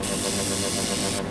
Index of /server/sound/weapons/tfa_cso/stunrifle
shootb.wav